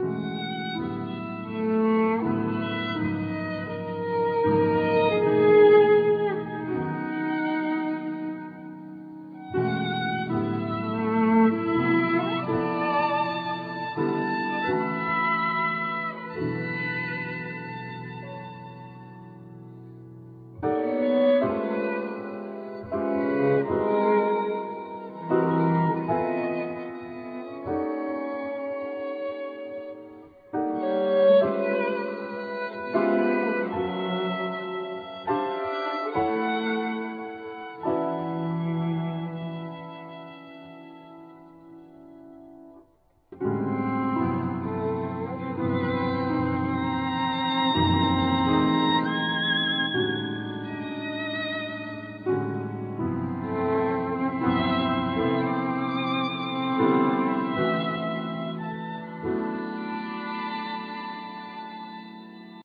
Piano
Violin
Cello
Tuba
Percussins